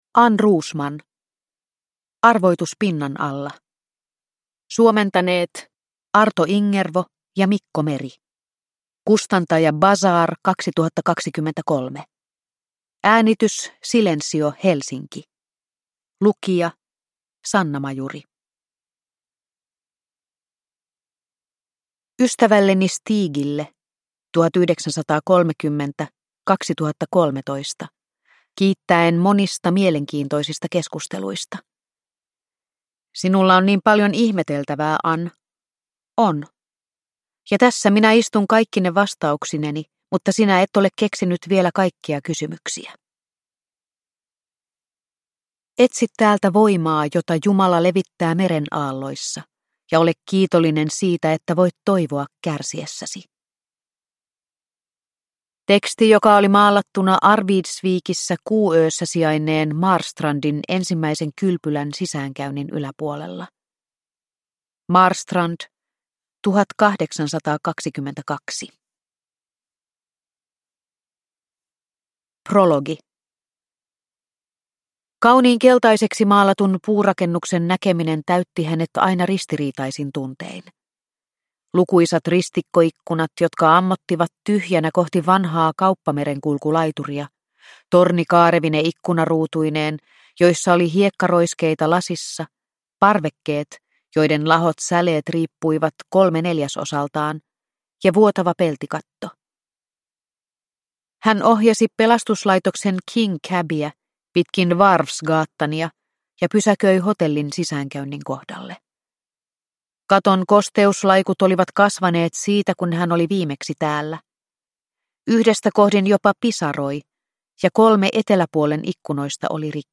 Arvoitus pinnan alla – Ljudbok – Laddas ner